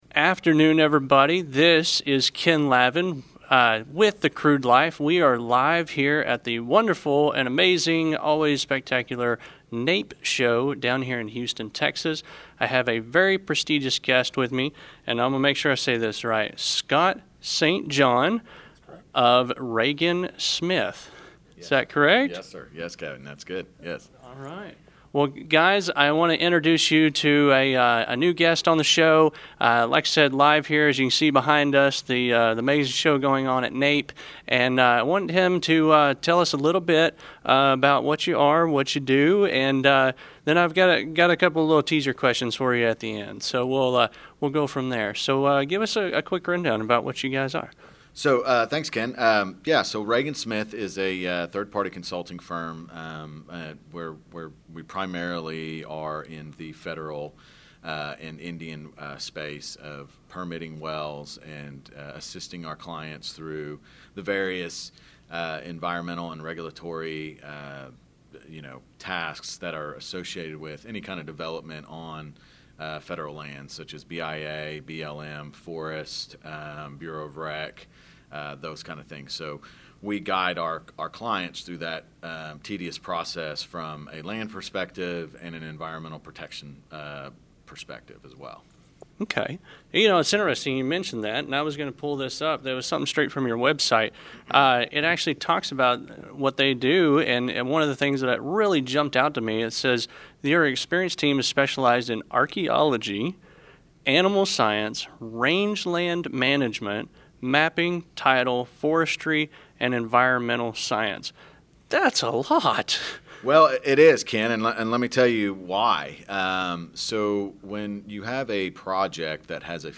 Full Length Interviews